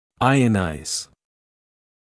[‘aɪənaɪz][‘айэнайз]ионизировать